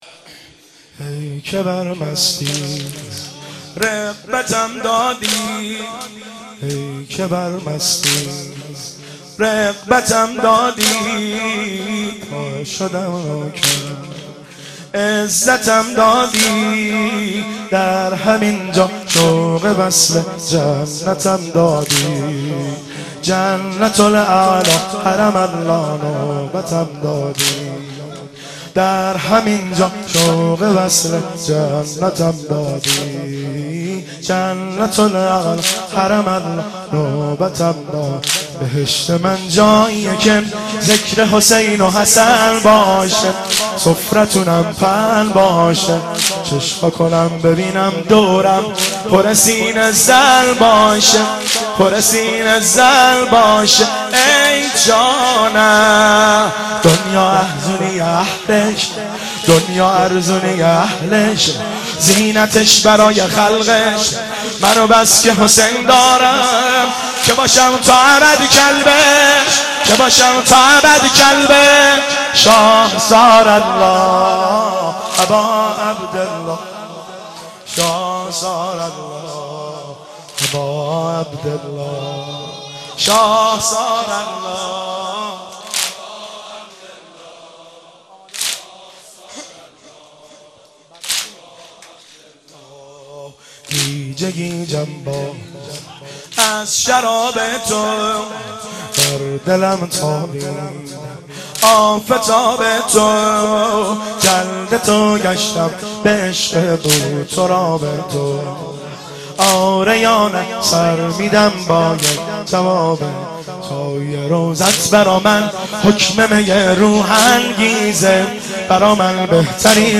شب سوم فاطميه 95 - واحد - ای که بر مستی رغبتم دادی